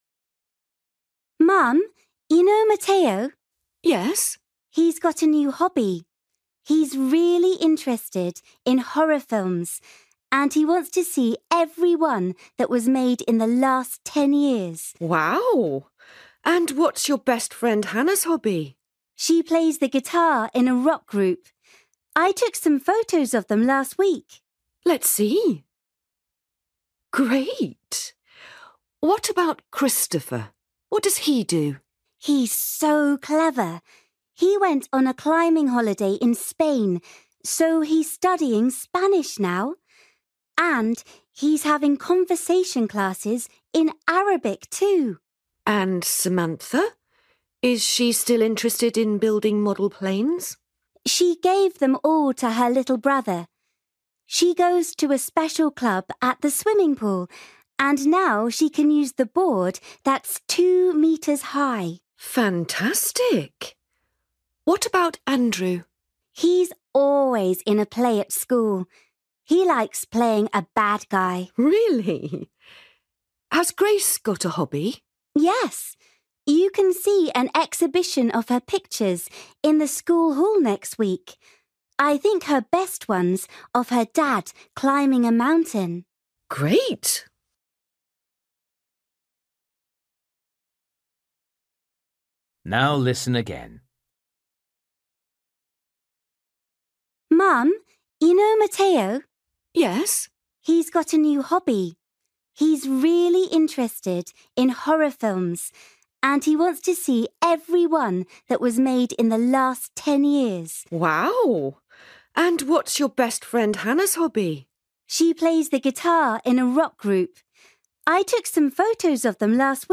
You will hear a girl talking to her mum about her friends and their hobbies.